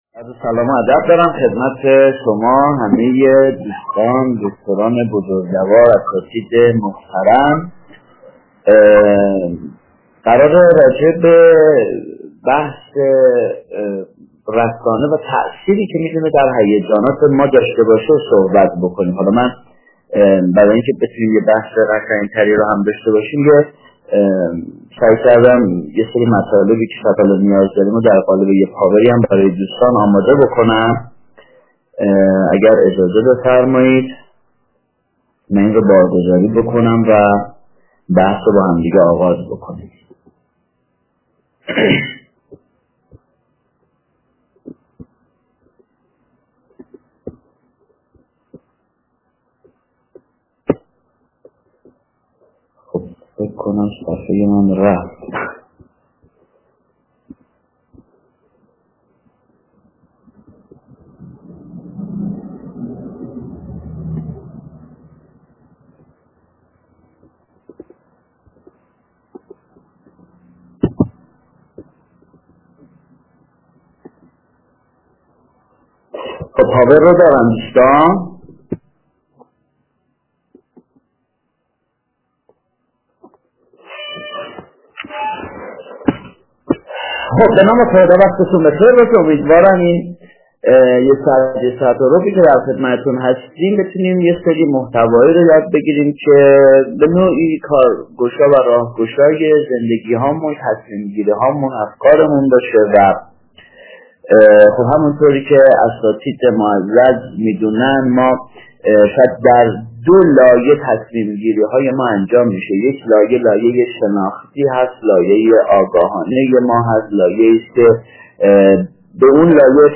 روز دوم دهمین مرحله طرح دانش‌افزایی ضیافت اندیشه استادان دانشگاه، کارگاه‌ آموزشی با عنوان «رسانه و فضای مجازی» برگزار شد.